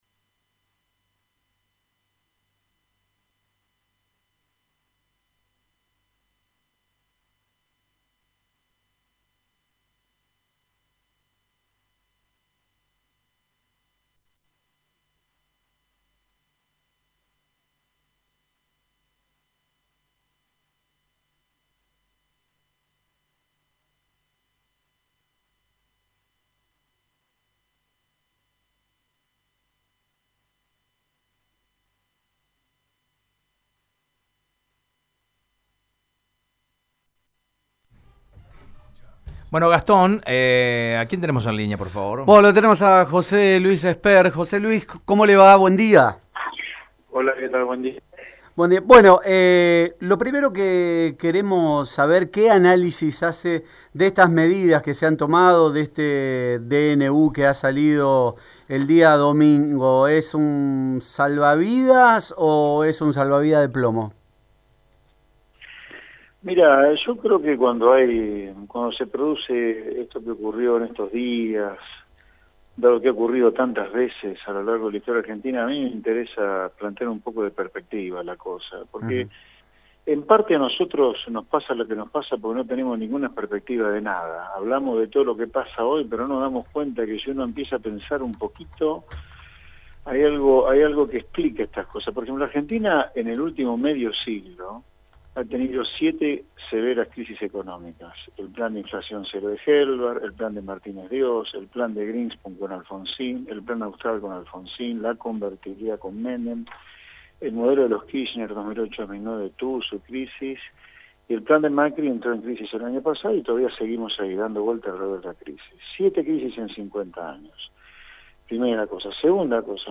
Este paquete de medidas generó grandes polémicas, el candidato presidencial José Luis Espert dio su punto de vista en Radio EME